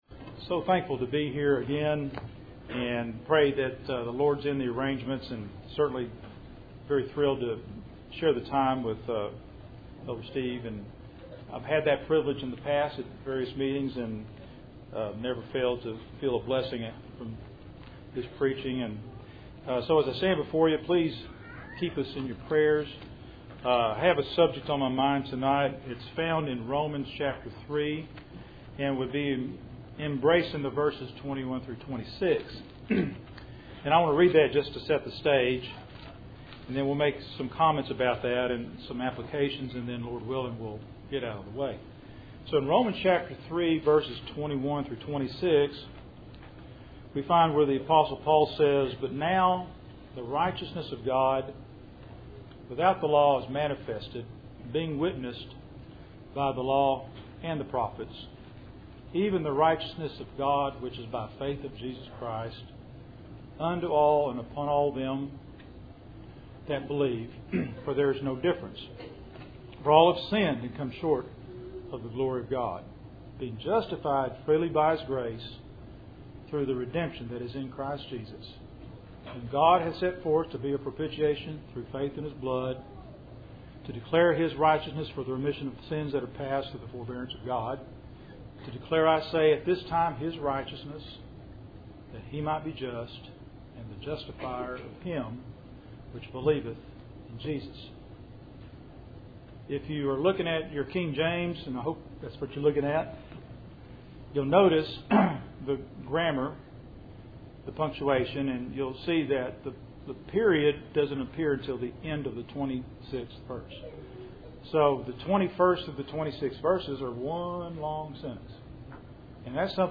Service Type: Cool Springs PBC August Annual Meeting